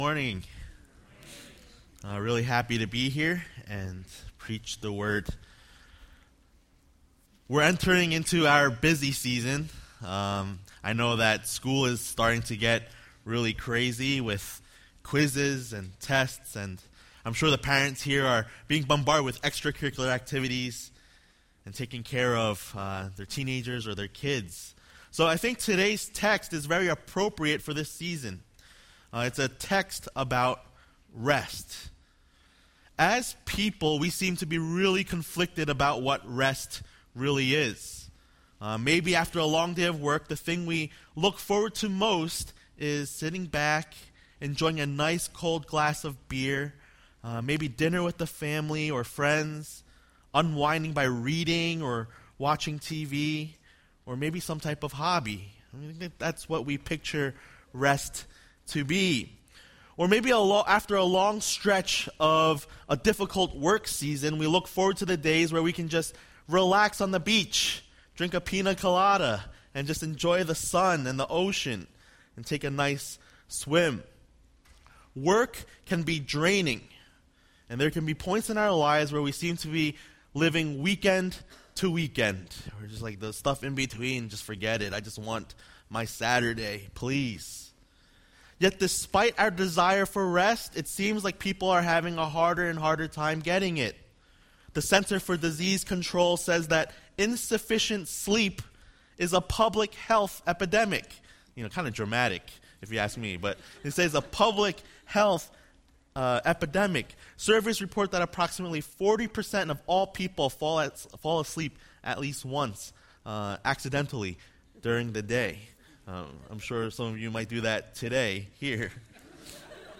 A message from the series "General Topics."